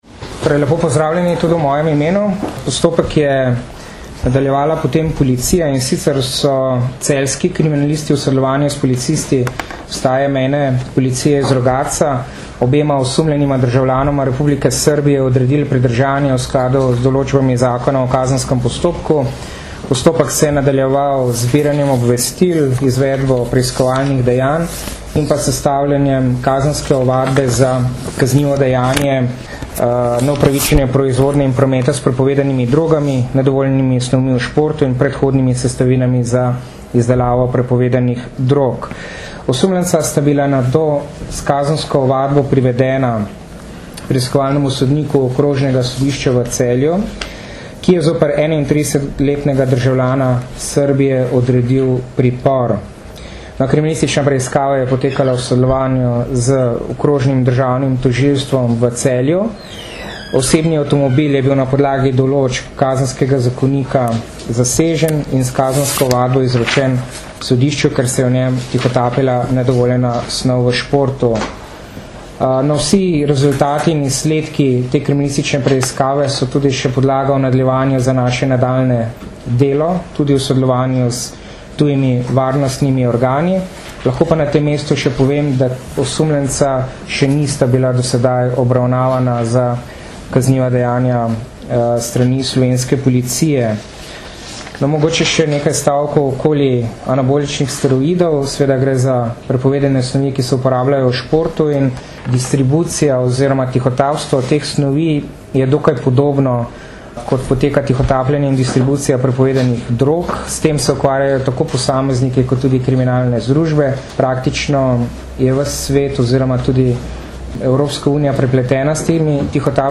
Predstavnika slovenske policije in carine sta na današnji novinarski konferenci na Carinskem uradu Celje razkrila podrobnosti nedavnega odkritja večje količine prepovedanih anaboličnih steroidov na Mejnem prehodu za mednarodni promet Dobovec.
Zvočni posnetek izjave